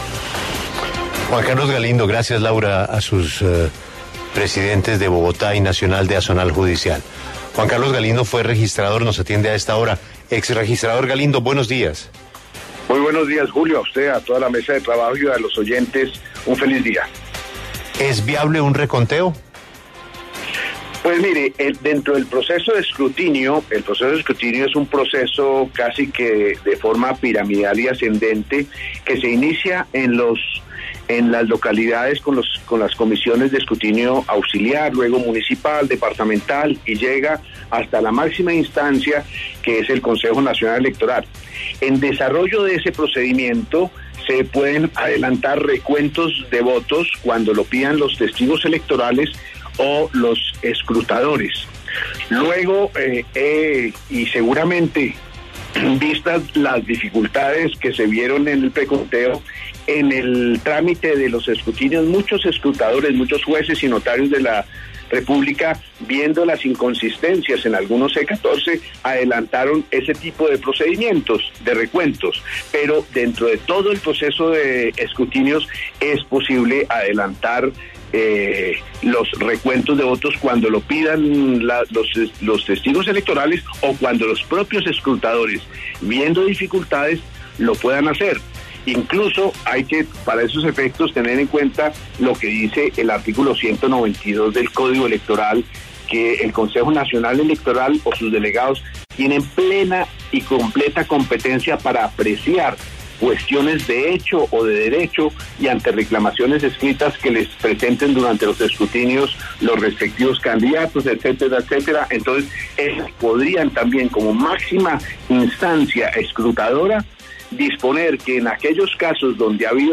En diálogo con La W, el exregistrador nacional Juan Carlos Galindo explicó cómo procede el sistema de reconteo de votos en medio de la atmósfera de desconfianza que hay en el país.